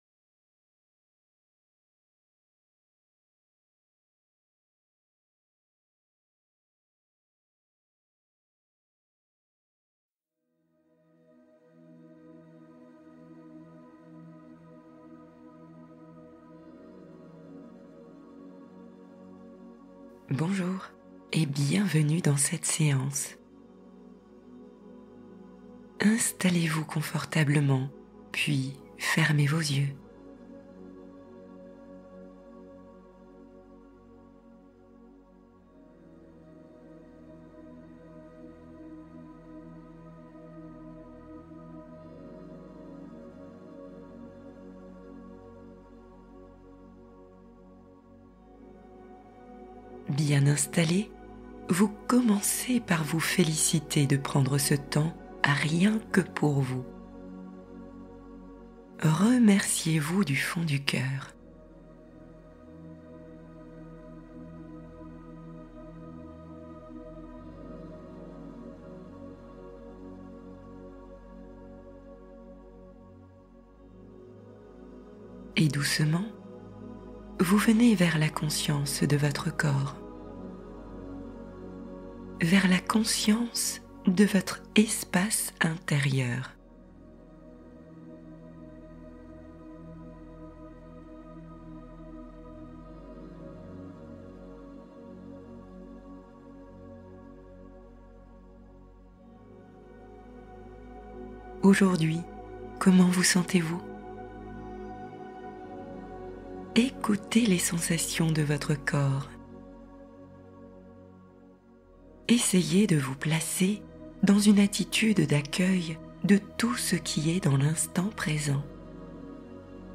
La sirène enchantée : relaxation guidée pour l’imaginaire des enfants